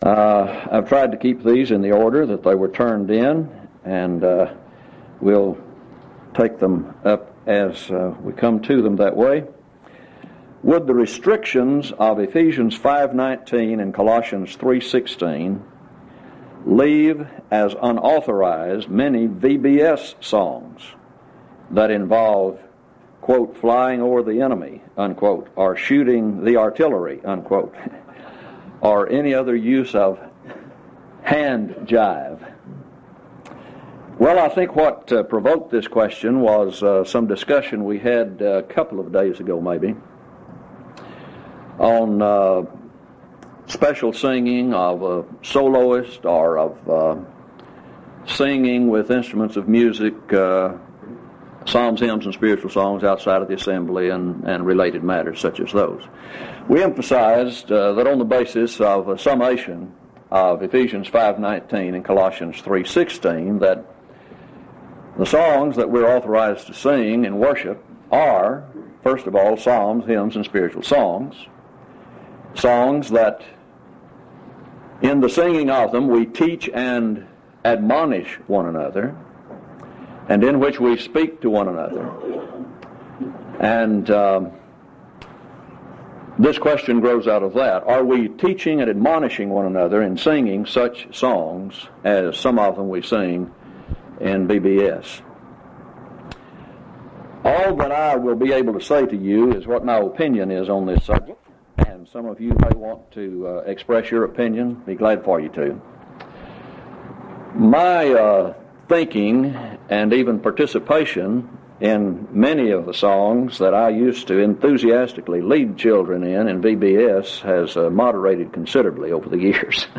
Title: Open Forum Speaker(s): Various
Event: 2nd Annual Lubbock Lectures Theme/Title: Looking Unto Jesus -- The Author & Finisher of Our Faith